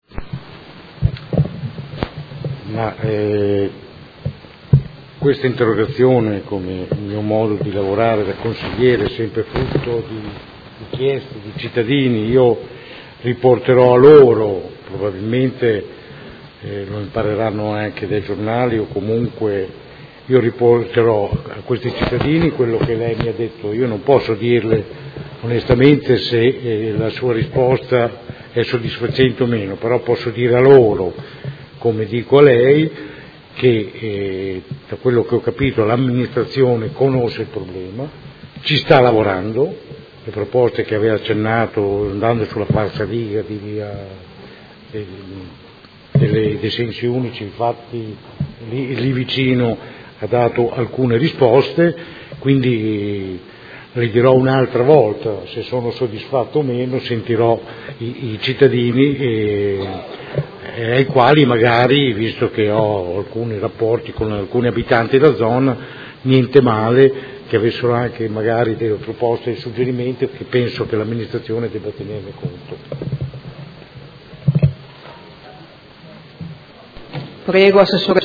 Seduta del 21/12/2017. Dibattito su interrogazione del Consigliere Rocco (Art.1-MDP/Per Me Modena) avente per oggetto: Disagio viabilità Via La Spezia/Via Oneglia